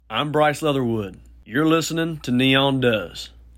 LINER Bryce Leatherwood (Neon Does) 3
LINER-Bryce-Leatherwood-Neon-Does-3.mp3